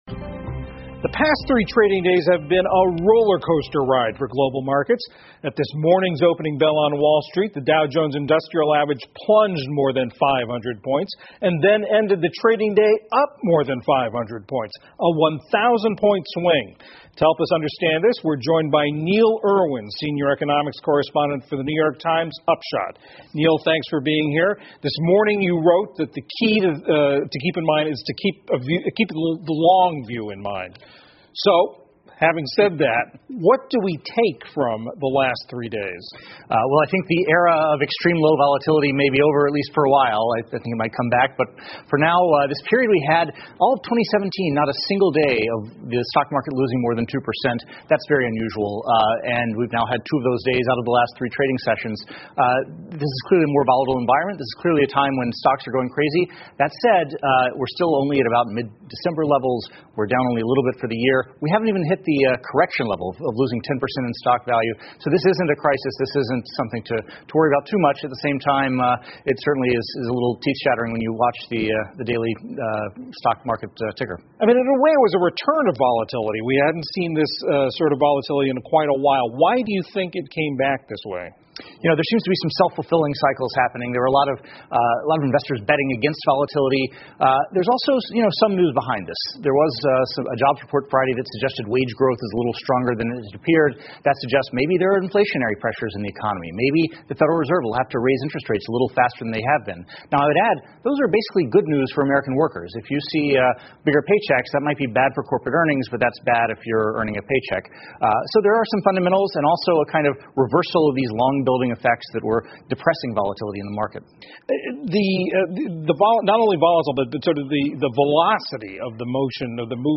美国公共电视网PBS高端访谈节目，其所提供的高质量节目与教育服务，达到媒体告知(inform)、启发(inspire)与愉悦(delight)的社会责任。